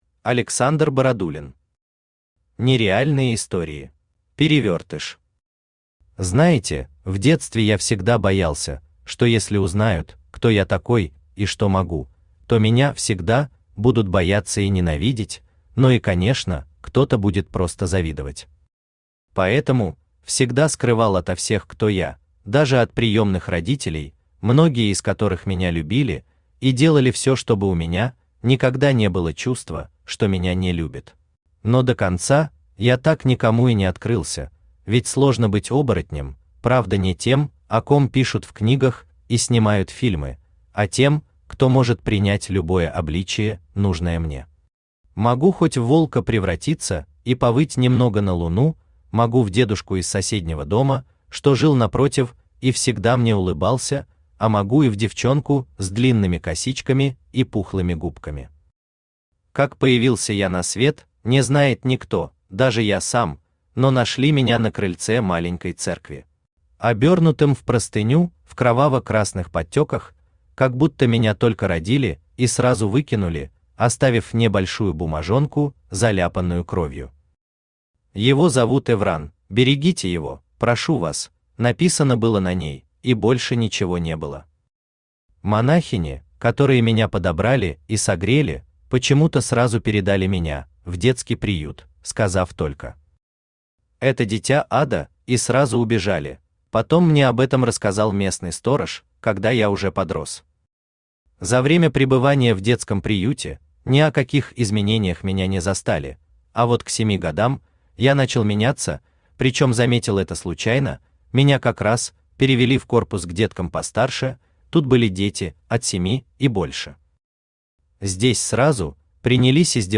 Аудиокнига Нереальные истории | Библиотека аудиокниг
Читает аудиокнигу Авточтец ЛитРес.